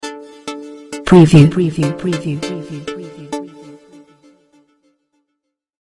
Gentle, enigmatic radio-TV stinger